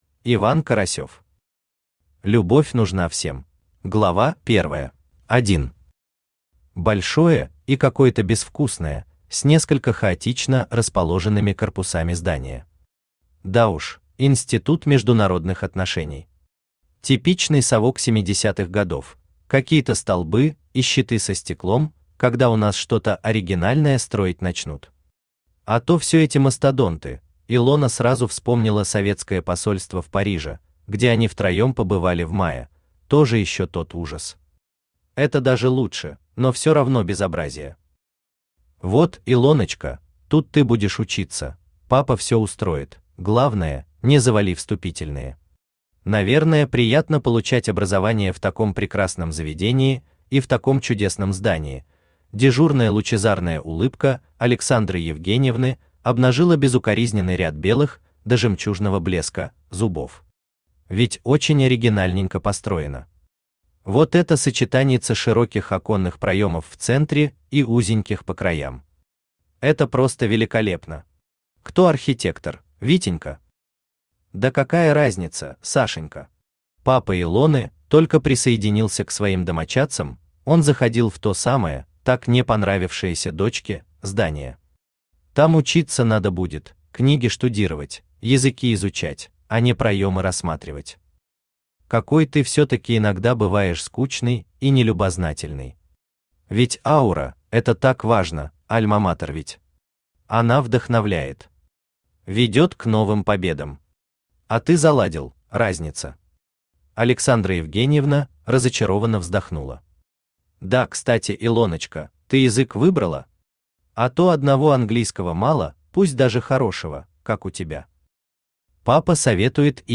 Аудиокнига Любовь нужна всем | Библиотека аудиокниг
Aудиокнига Любовь нужна всем Автор Иван Карасёв Читает аудиокнигу Авточтец ЛитРес.